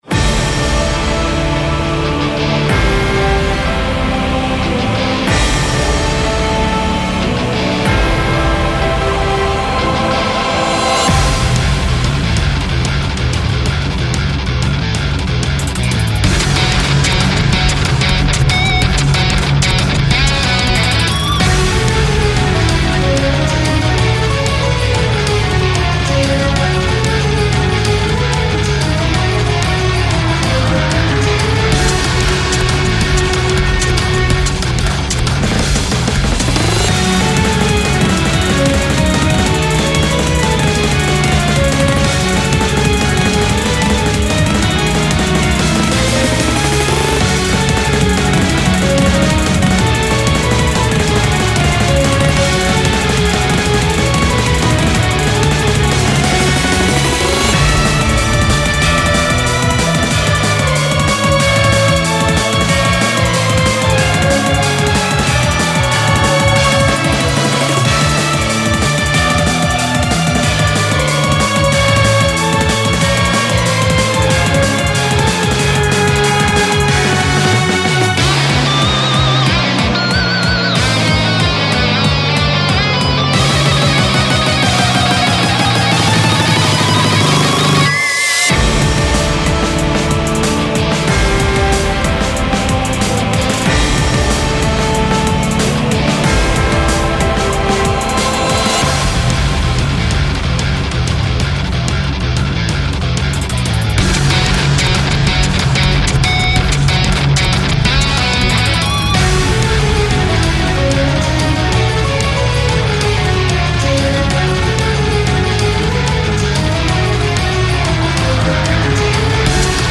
ジャンルゴシック、サイバー
使用例ボス戦闘曲、音ゲー
BPM１８６
使用楽器ギター、バイオリン、ピアノ、ブラス、クワイヤー
解説荘厳でスピード感のあるゴシック戦闘曲フリーBGMです。